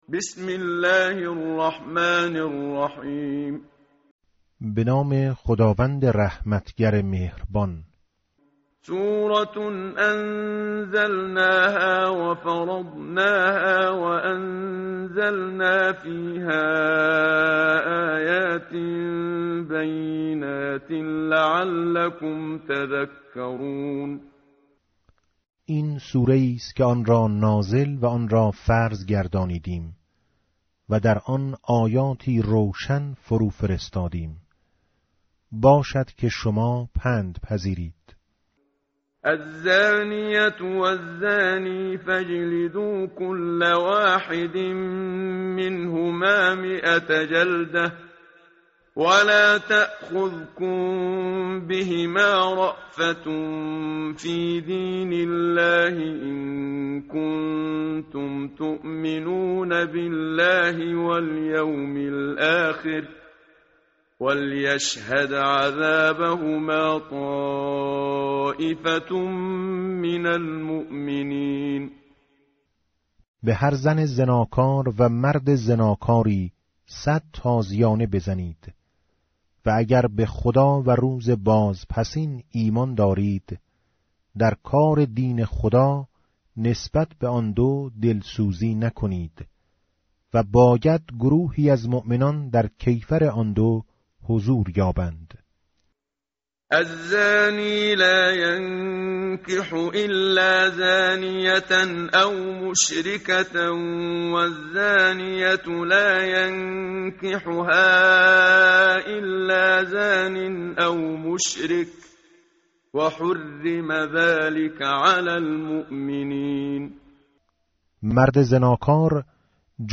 متن قرآن همراه باتلاوت قرآن و ترجمه
tartil_menshavi va tarjome_Page_350.mp3